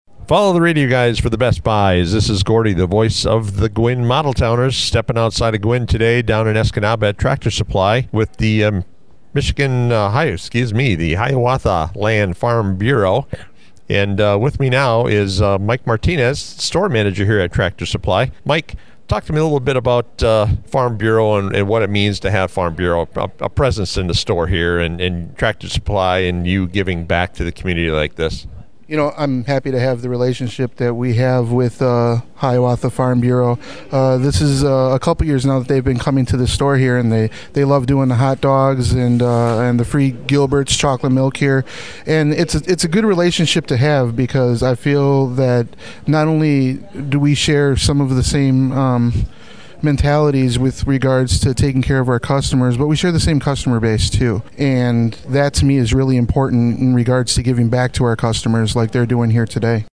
It looked to be a hectic day, as more and more people flooded into the Escanaba Tractor Supply Company’s showroom!